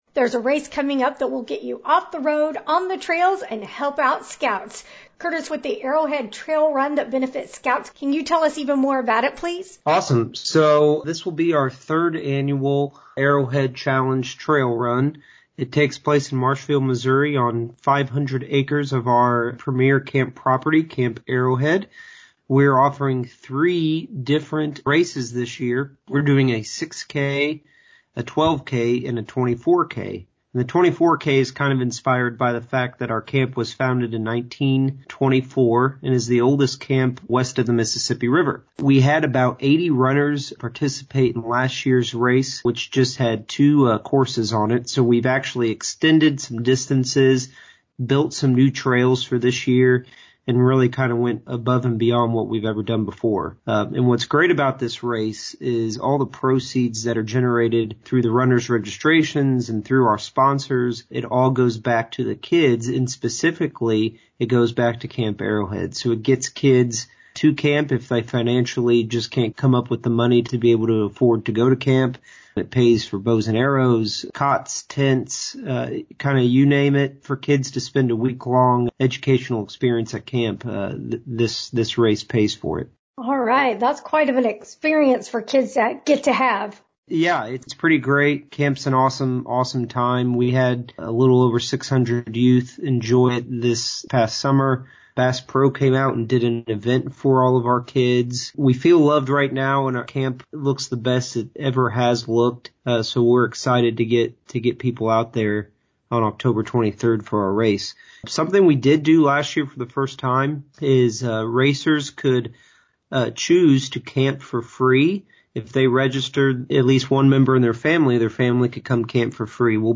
Interview
Arrowhead-Interview-2021.mp3